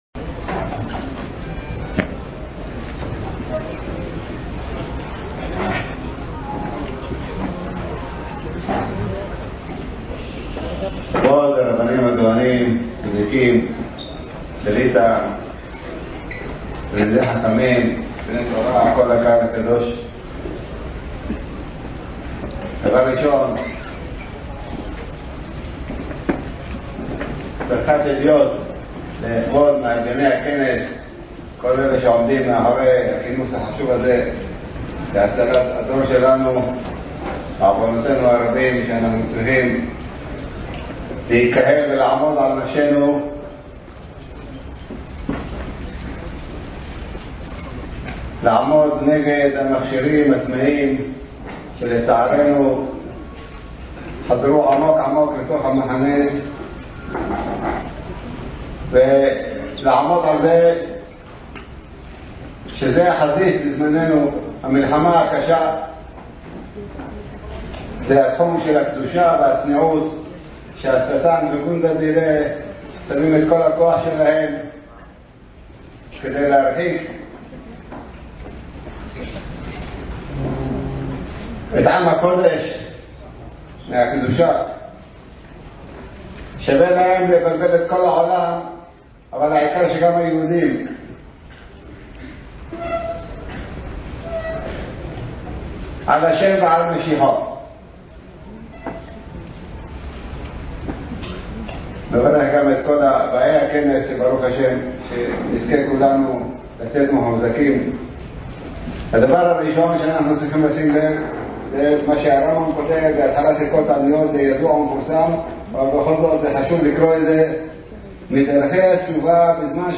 וידיאו! דברי חיזוק והתעוררות מפי מרן שליט"א בכנס חיזוק והסברה בענייני מפגעי הטכנולוגיה